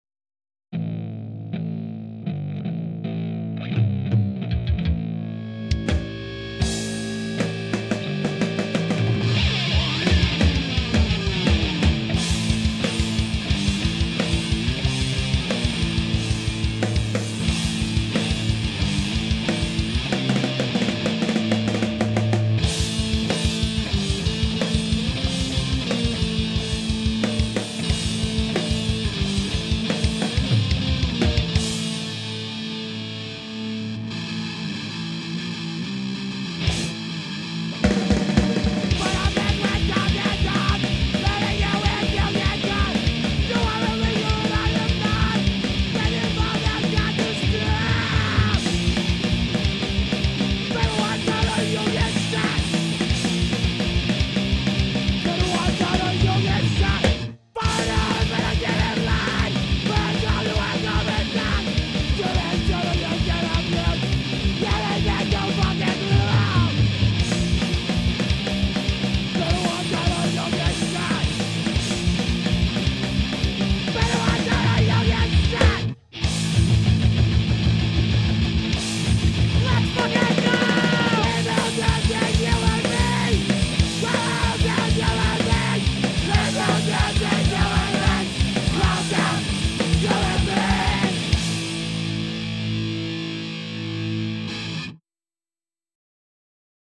fast boston hardcore